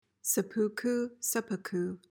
PRONUNCIATION:
(se-POO-koo, SE-puh-koo)